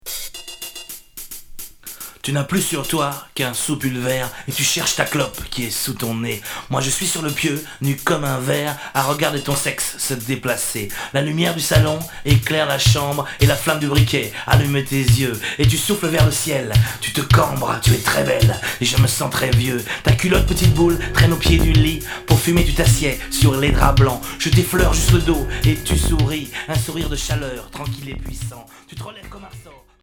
Alternatif